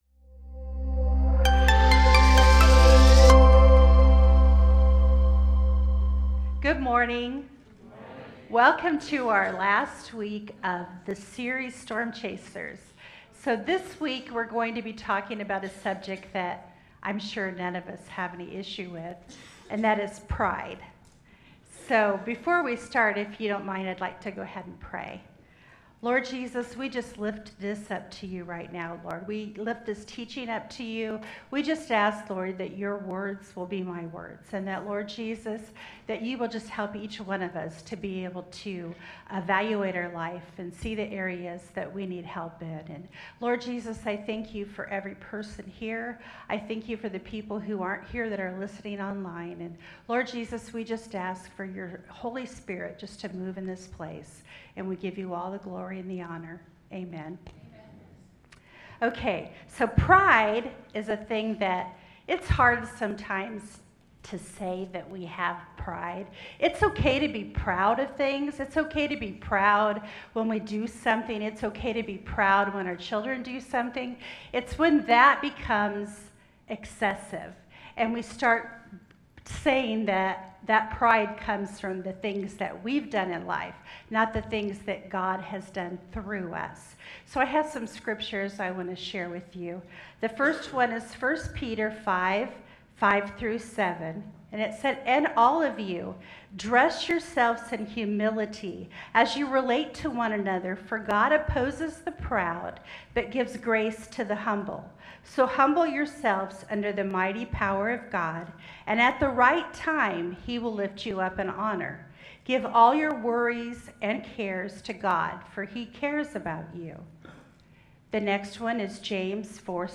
Sermons | Innovation Church